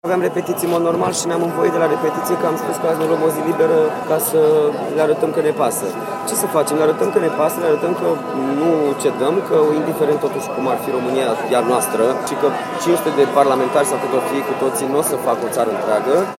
La ora 12.00 a început în fața Parlamentului protestul convocat de Uniunea Salvați România.
Între manifestanți – actorul Marius Manole, care a spus că a renunțat la repetiții pentru a protesta.